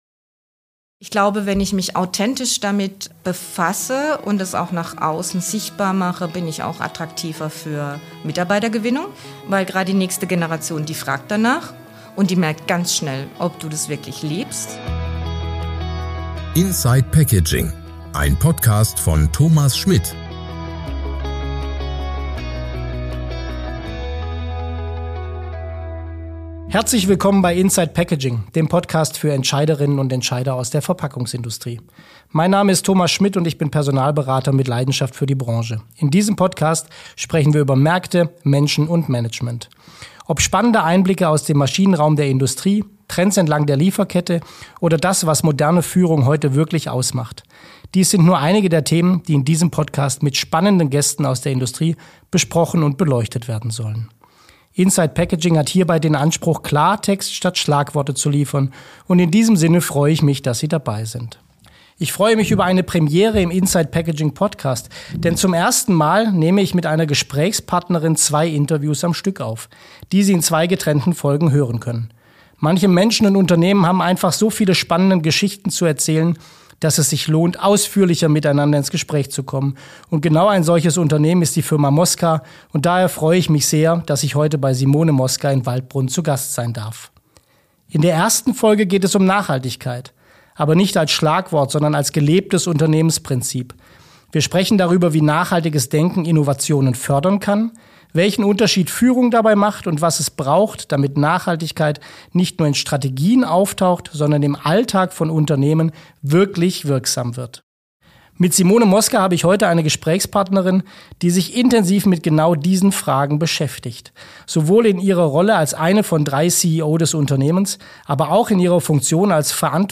Ein Gespräch über Wandel, Leadership und faktenbasierte Diskussionen in der Verpackungsindustrie.